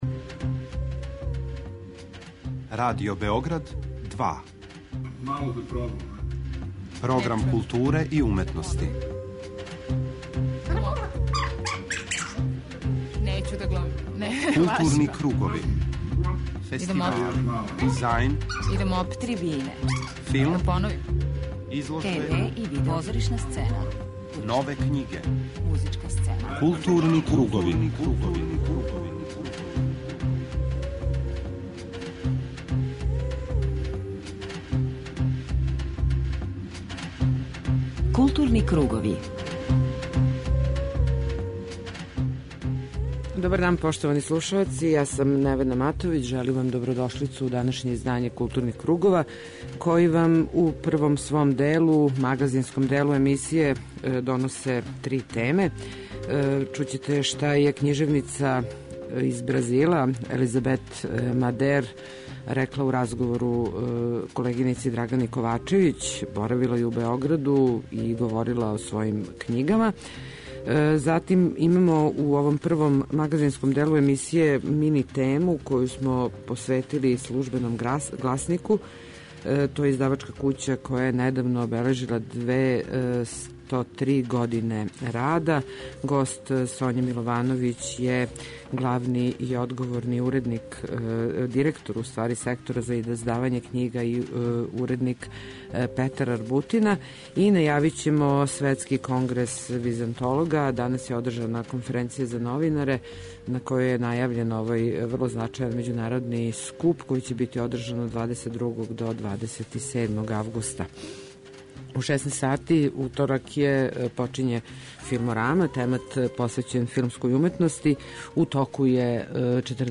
У данашњој емисији чућете најзанимљивије делове ове презентације.